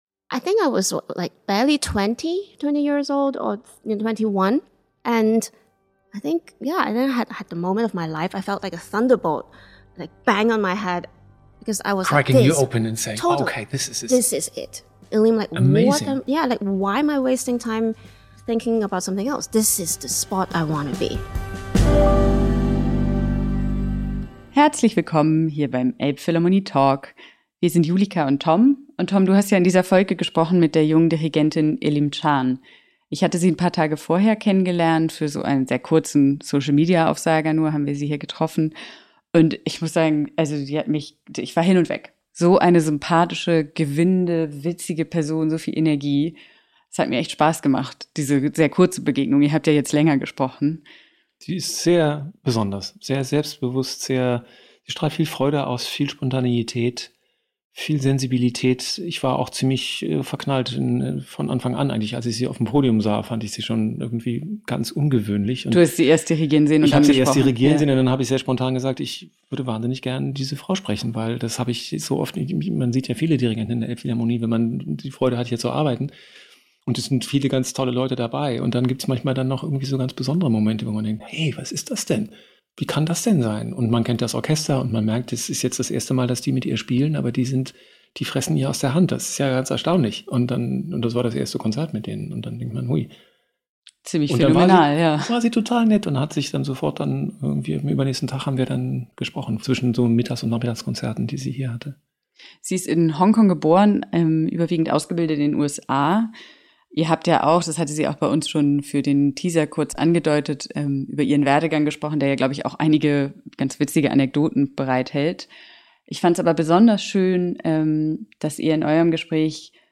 Dirigentin statt Spionin – Elim Chan im Gespräch